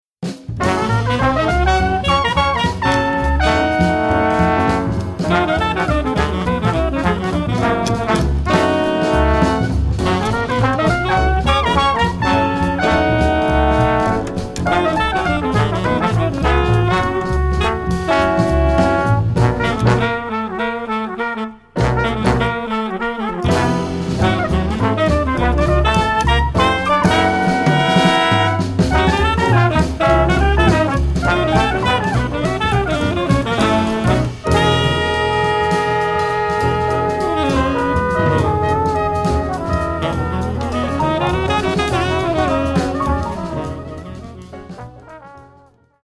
Voicing: 3Horns/Rhy